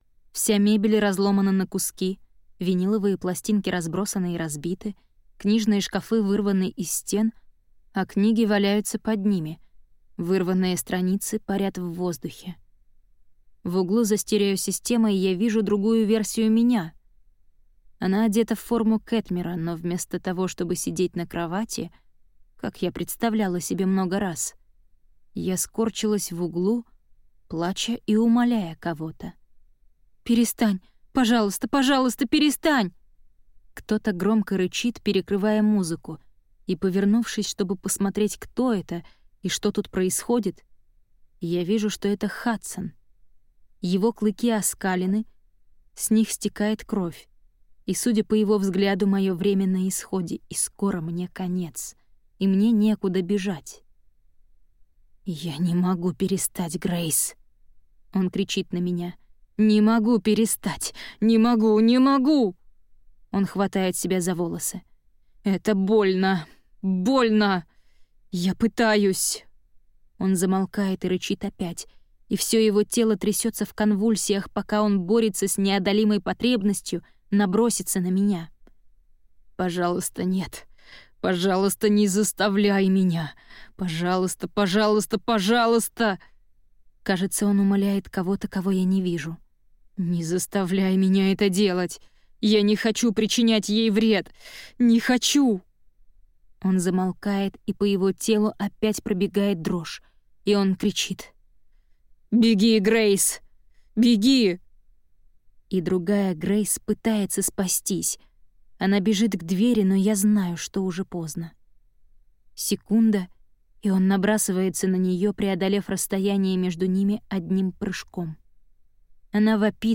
Аудиокнига Желание | Библиотека аудиокниг